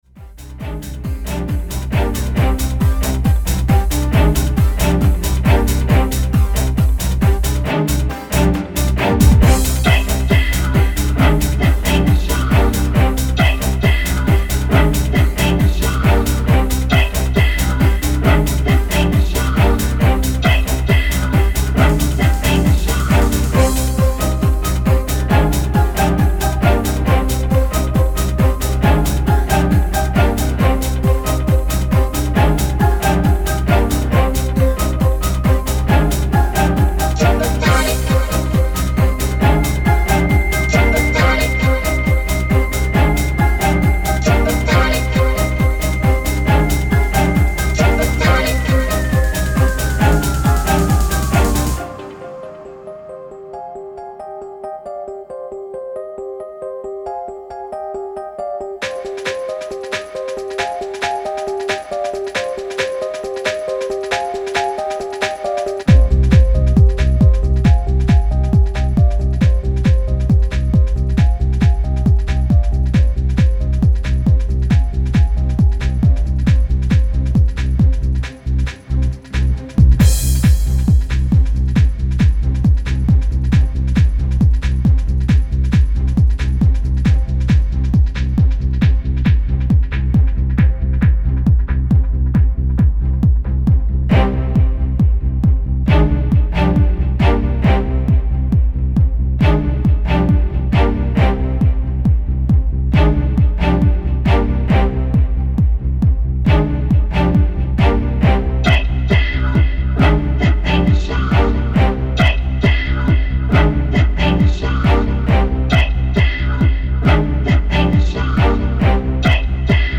Жанр: Electronica-Trance